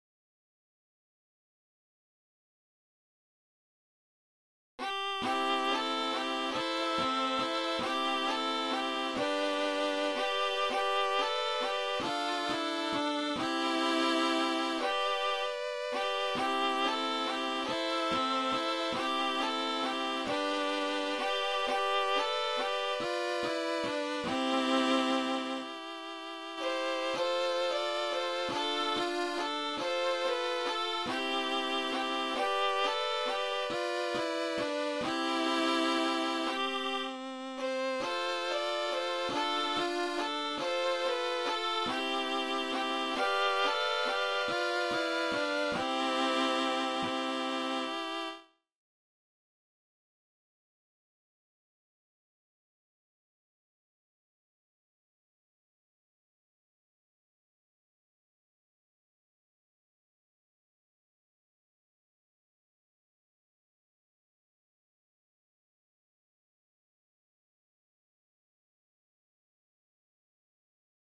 Hyttysen ininää